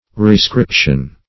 Rescription \Re*scrip"tion\ (r?-skr?p"sh?n), n. [L. rescriptio: